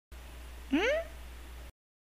Play, download and share Humm? original sound button!!!!
hum_2.mp3